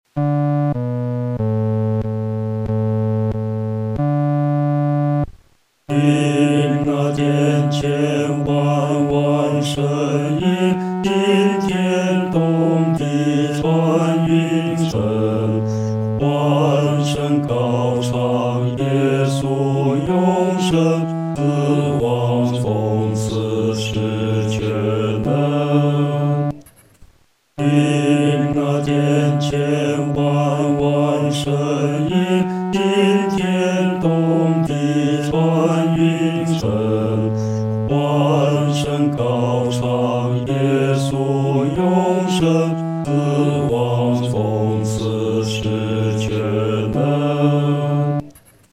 男低合唱